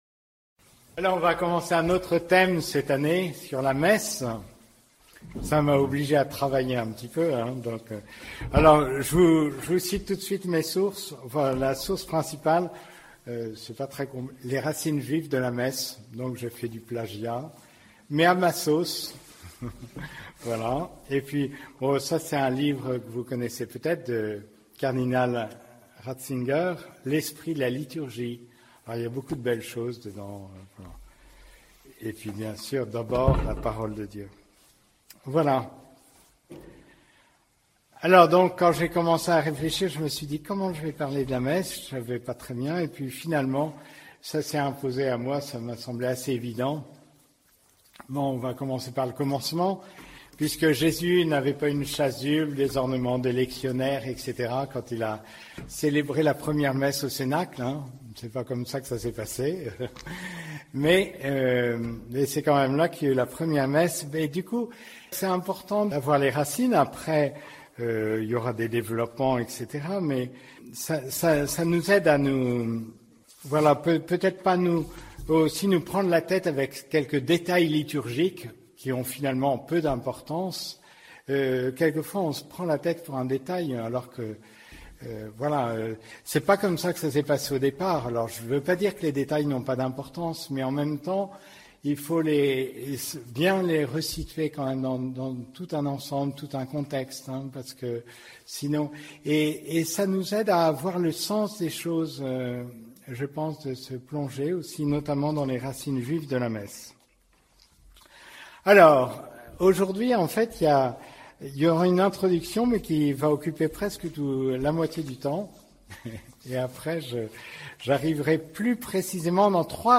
Cet enseignement est consacré aux racines juives de la messe.